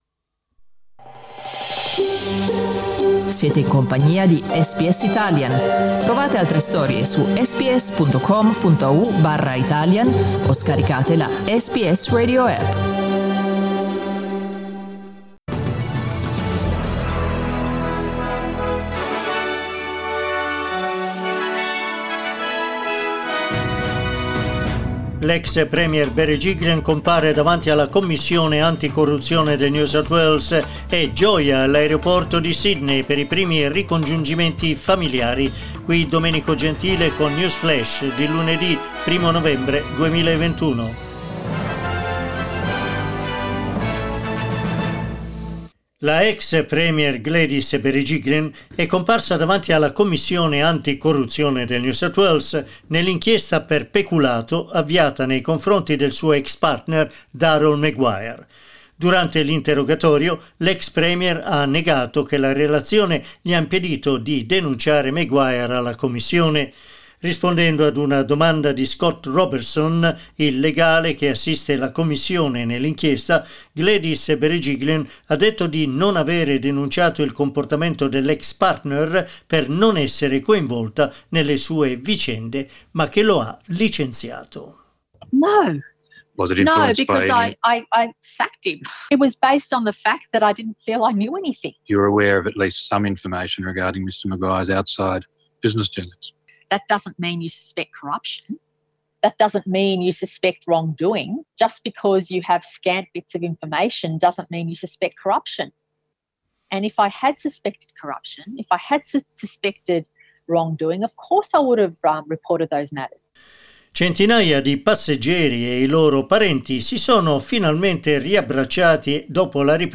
News flash lunedì 1 novembre 2021